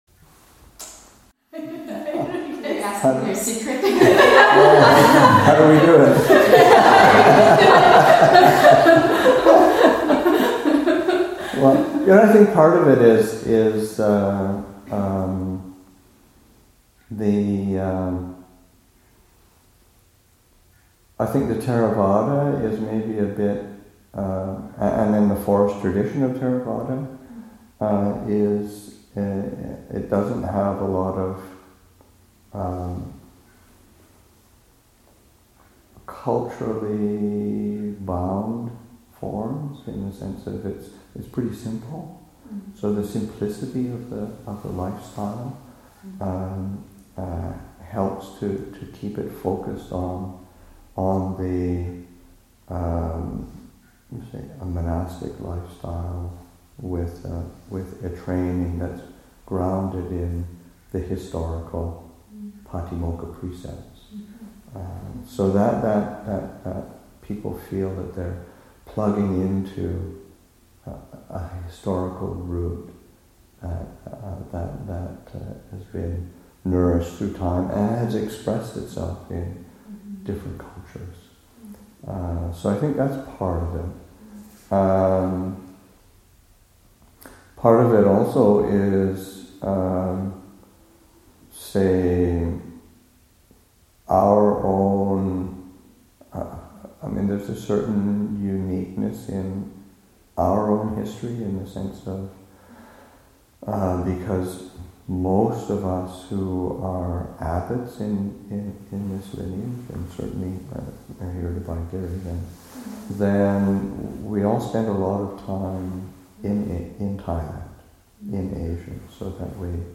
Q&A session, Nov. 18, 2014